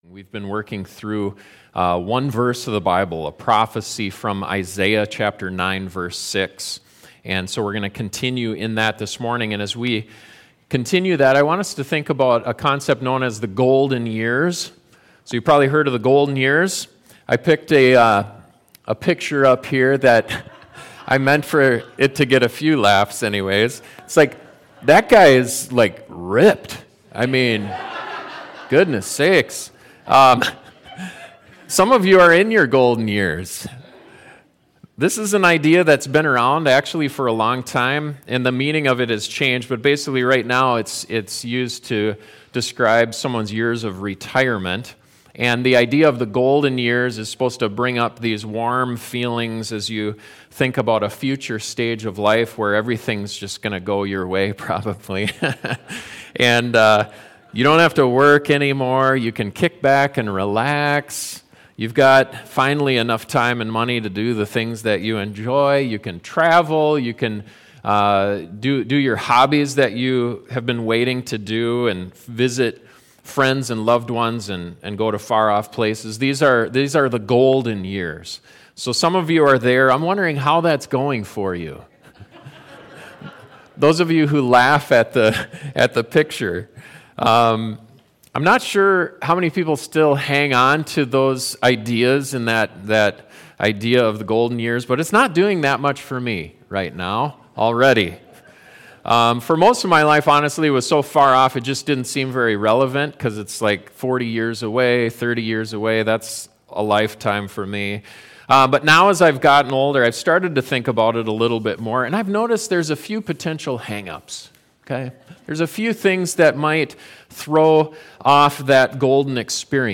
Where will your hope come from? This sermon examines the only source that can satisfy that longing deep within.